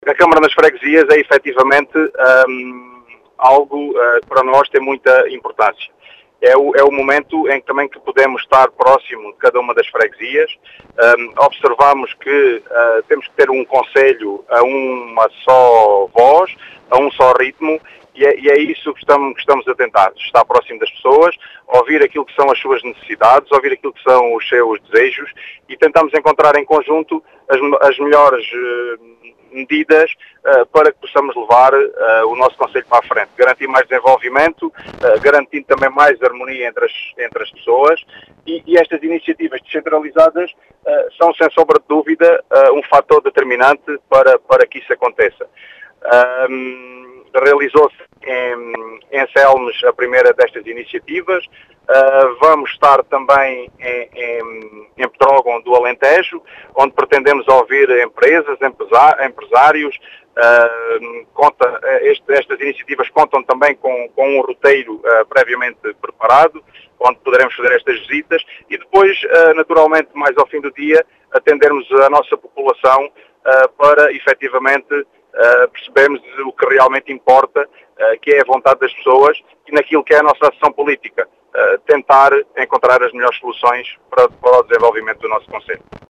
Em declarações à Rádio Vidigueira, o presidente da Câmara de Vidigueira, Ricardo Bonito, realça a importância desta iniciativa para um concelho “a uma só voz”.